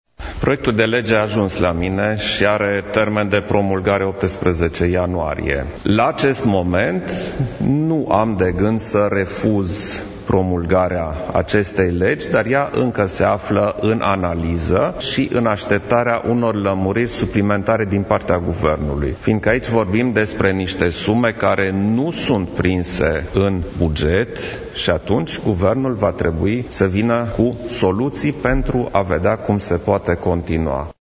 Preşedintele Klaus Iohannis a declarat, în această după amiază, că nu are de gând, la acest moment, să refuze promulgarea legii privind dublarea alocaţiilor pentru copii. Iohannis a precizat că proiectul de lege are termen de promulgare 18 ianuarie: